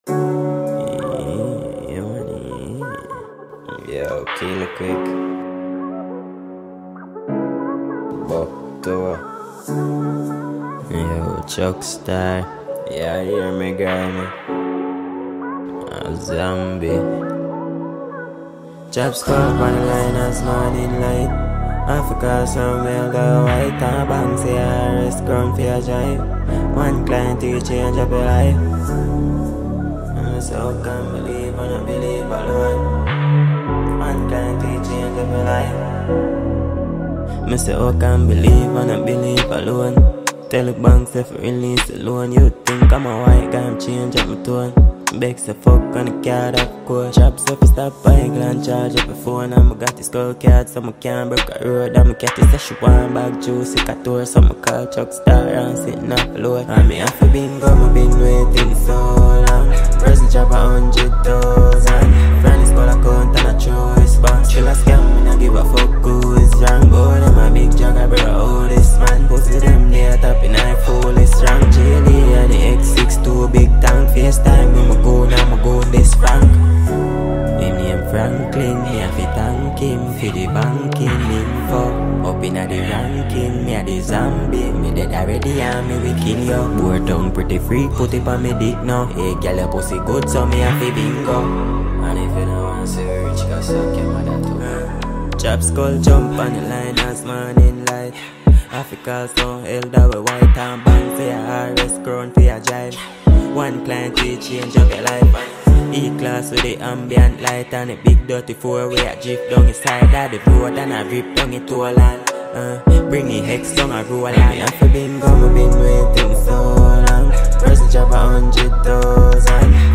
Nigerian singer-songsmith